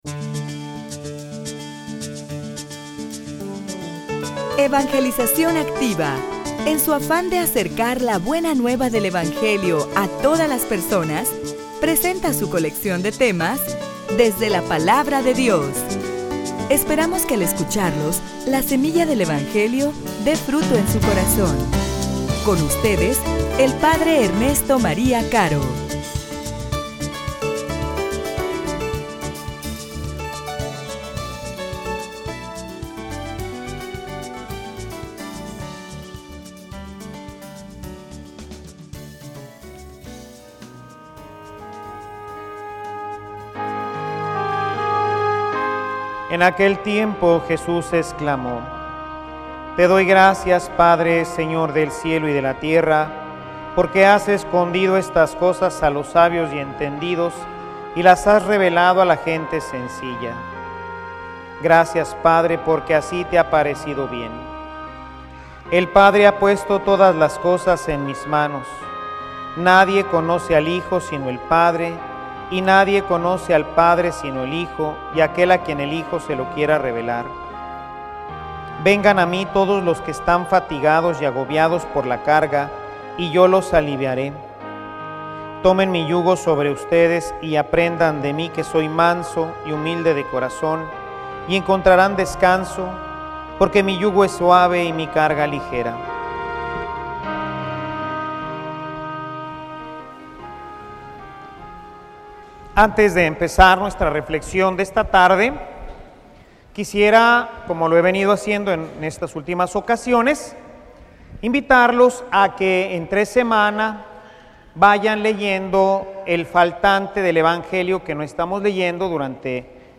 homilia_Vengan_a_mi_los_cansados.mp3